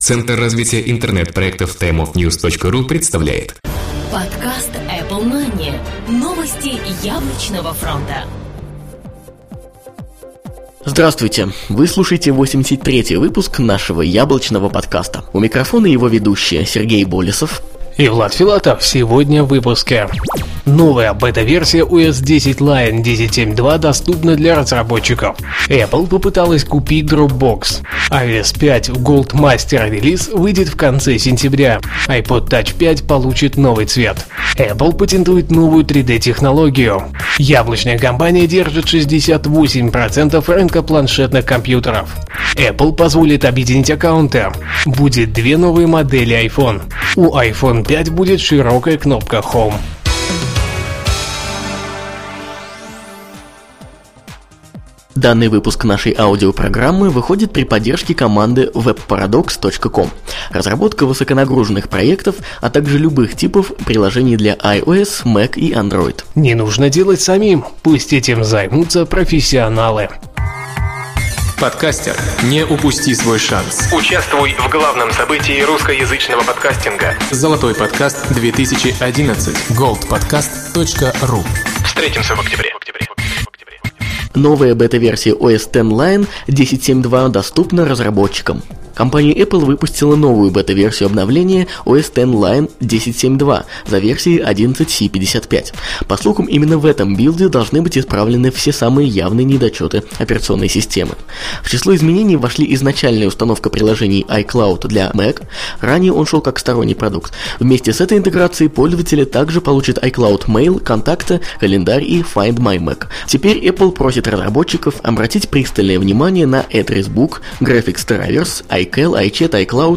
"Apple Mania" - еженедельный новостной Apple подкаст
Жанр: новостной Apple-podcast
Битрейт аудио: 80-96, stereo